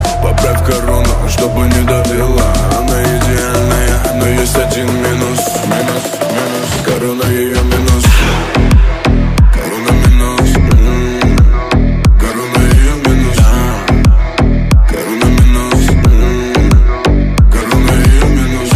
• Качество: 320, Stereo
басы
качающие
G-House